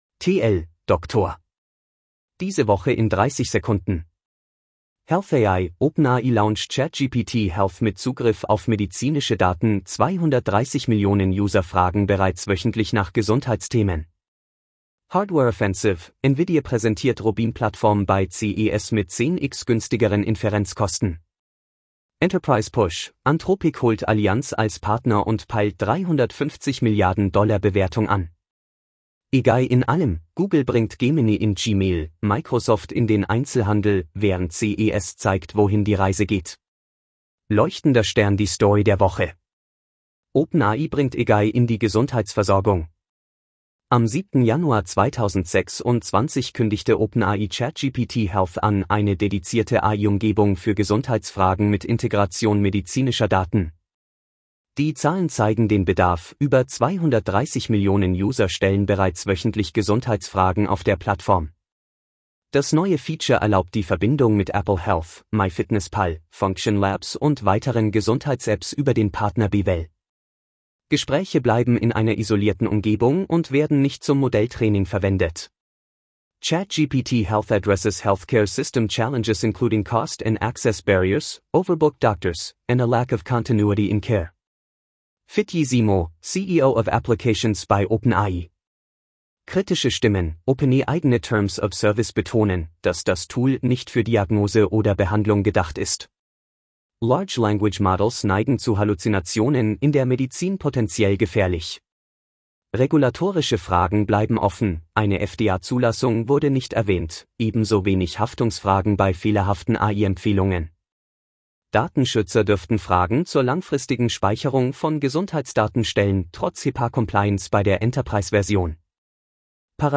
Vorgelesen mit edge-tts (Microsoft Azure Neural Voice: de-DE-ConradNeural)